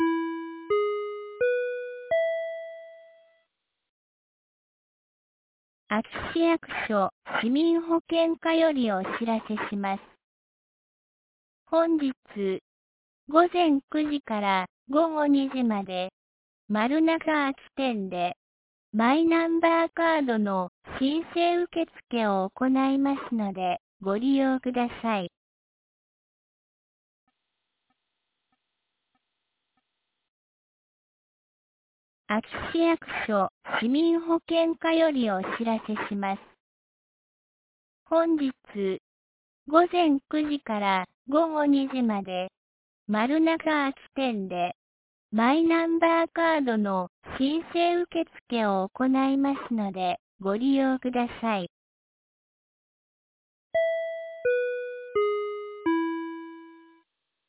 2024年06月09日 08時50分に、安芸市より全地区へ放送がありました。